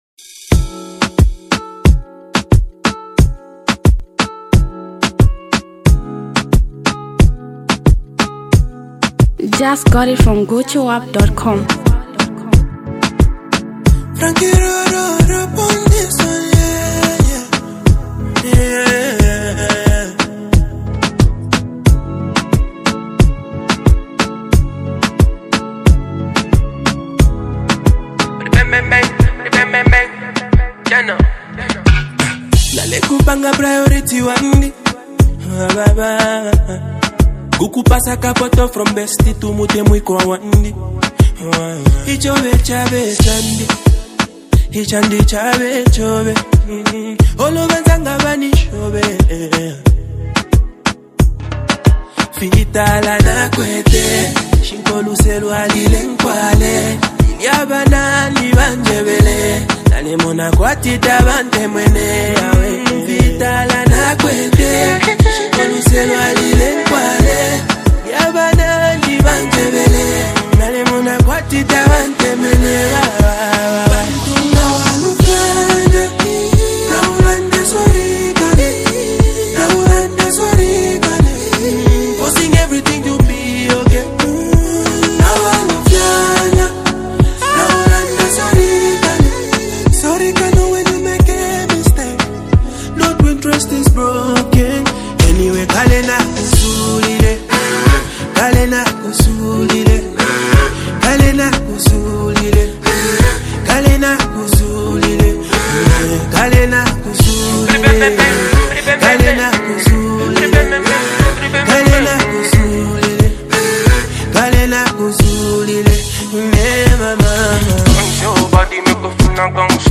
a heartfelt and lovable rhyme